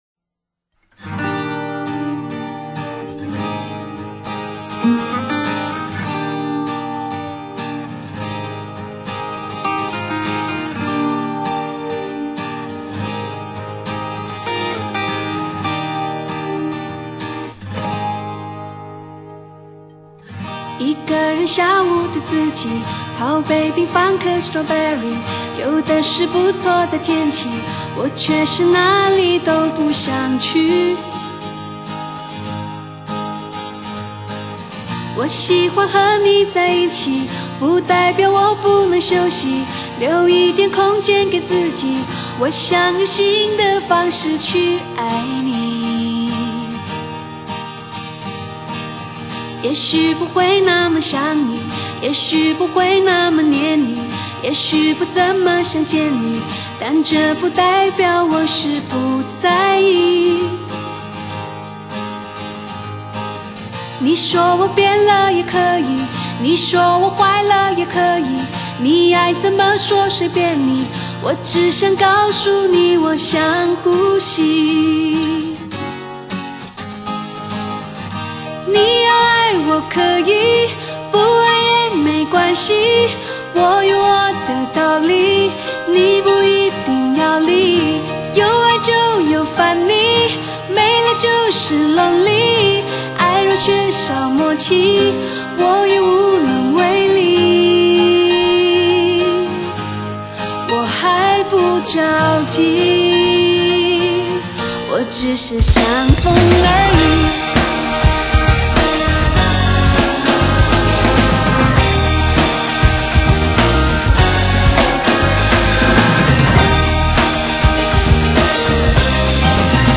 最爱吉他 随性有感情
五岁开始唱歌, 清甜的嗓音, 麻醉无数异地思乡的华人